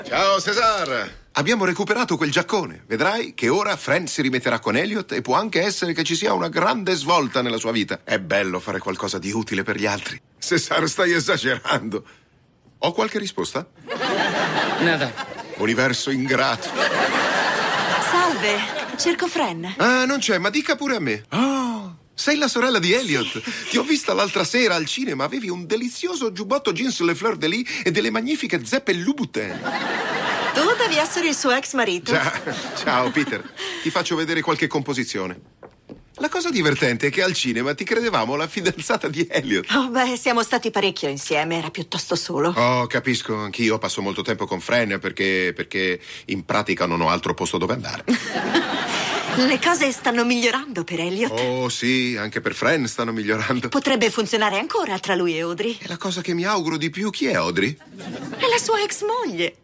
voce di Gioele Dix nel telefilm "Happily Divorced", in cui doppia John Michael Higgins.